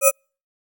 Holographic UI Sounds 36.wav